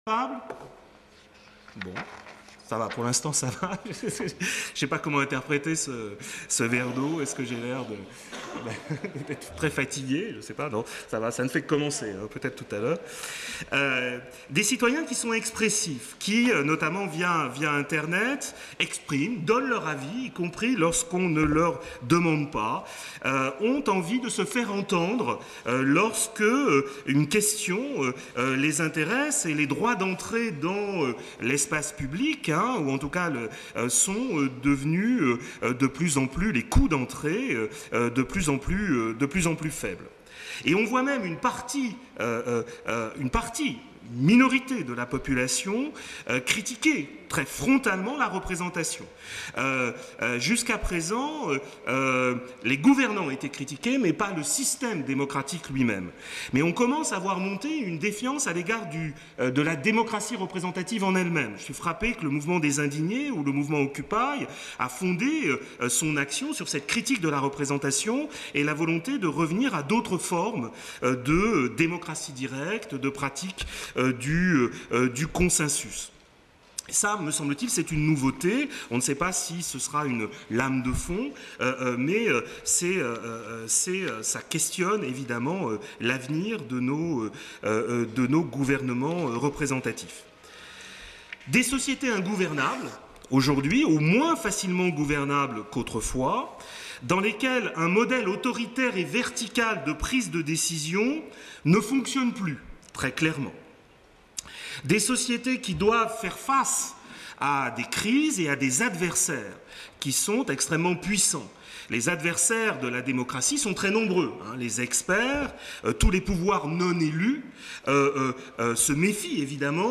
Colloque 23/01/2014 : SP 2